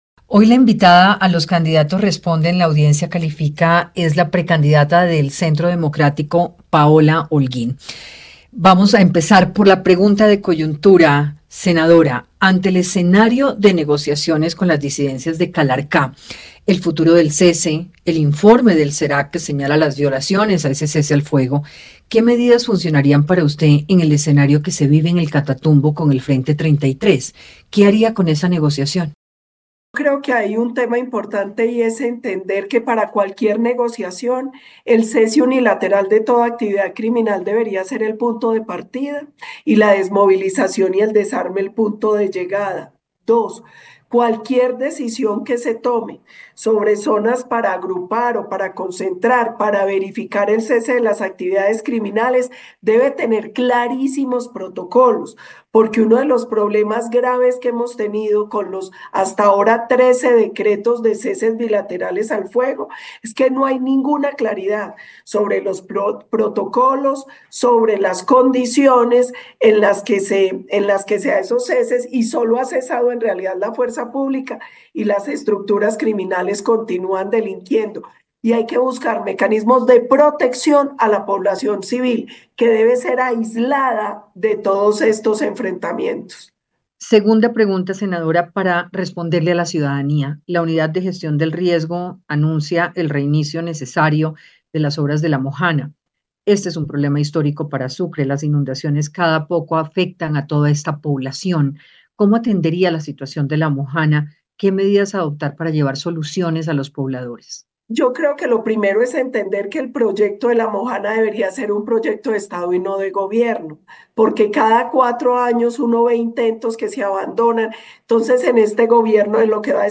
En diálogo con “Los candidatos responden, la audiencia califica” de Hora20, la precandidata presidencial por el Centro Democrático y senadora de la república, Paola Holguín habló sobre cómo enfrentar el diálogo que se desarrolla con las disidencias de alias “Calarcá” y una posible extensión del cese al fuego, “creo que hay un tema importante y es entender que para cualquier negociación el cese unilateral de toda actividad criminal debería ser el punto de partida y la desmovilización y el desarme el punto de llegada”.